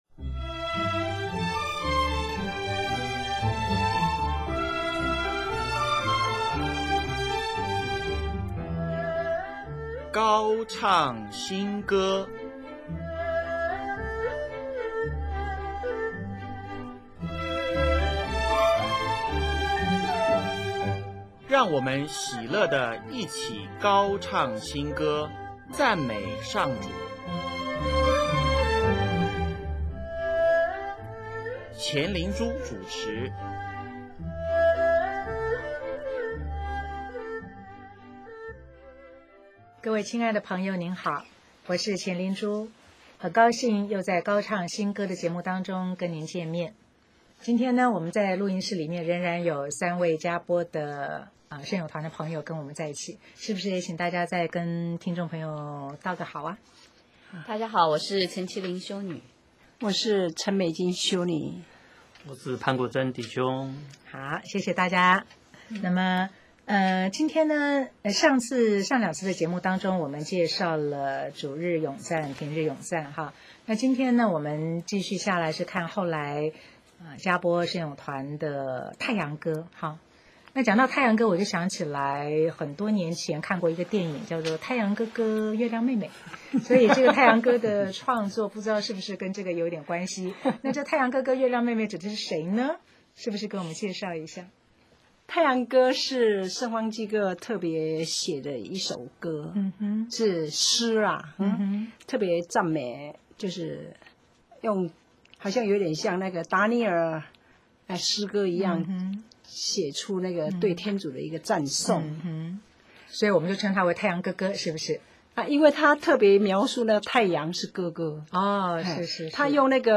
【高唱新歌】7|专访“佳播圣咏团”(三)：与生命保持和谐